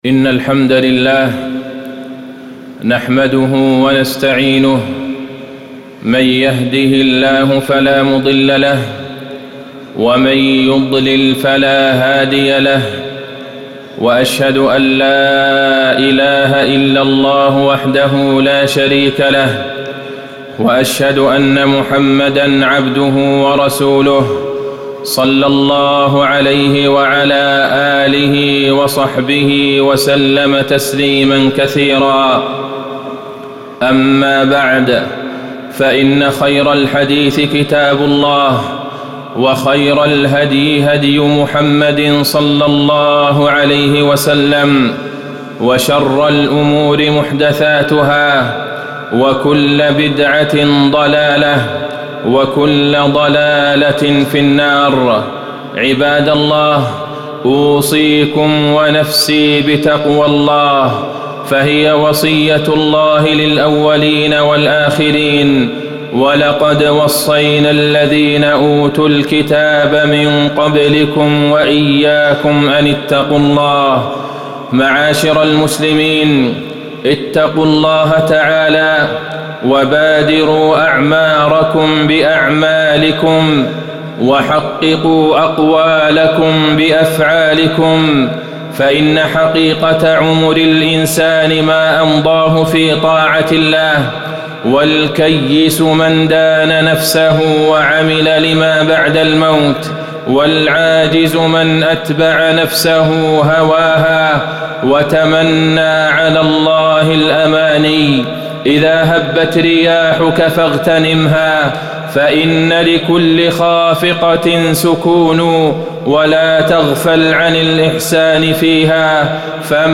تاريخ النشر ٨ شوال ١٤٣٩ هـ المكان: المسجد النبوي الشيخ: فضيلة الشيخ د. عبدالله بن عبدالرحمن البعيجان فضيلة الشيخ د. عبدالله بن عبدالرحمن البعيجان اغتنام الفرص والأوقات